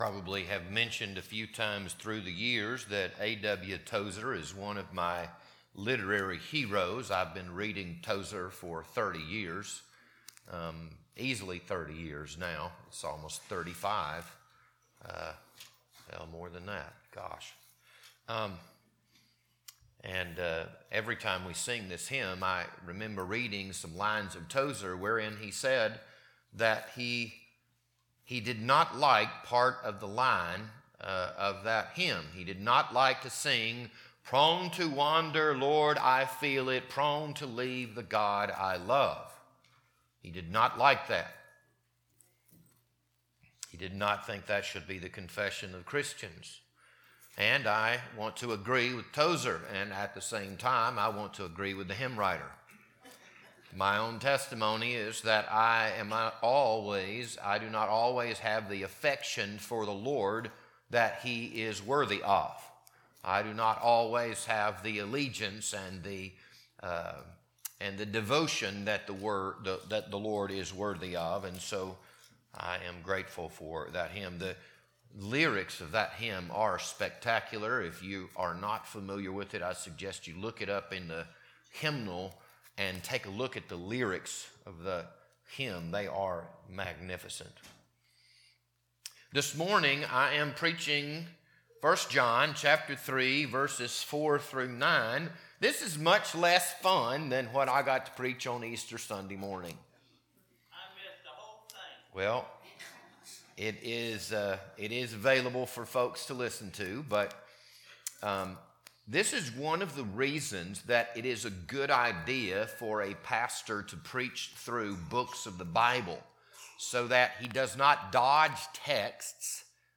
This Sunday morning sermon was recorded on April 12th, 2026.